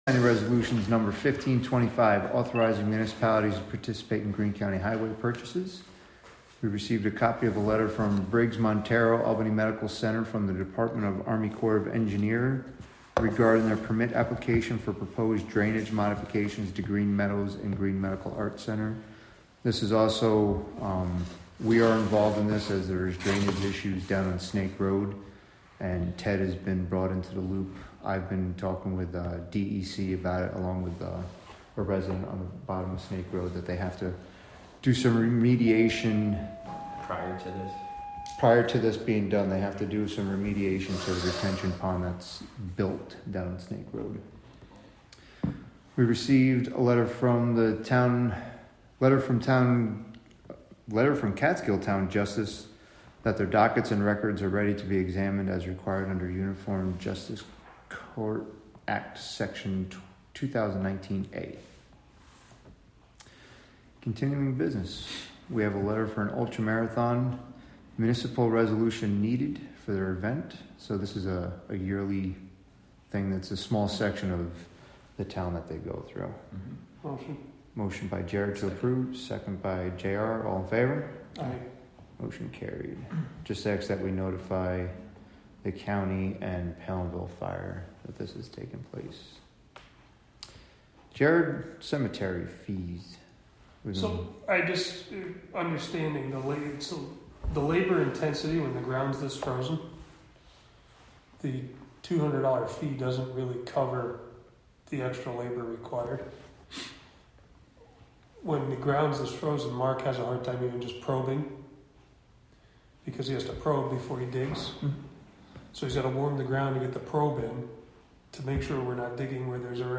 Live from the Town of Catskill: February 4, 2025 Catskill Town Board Meeting (Audio)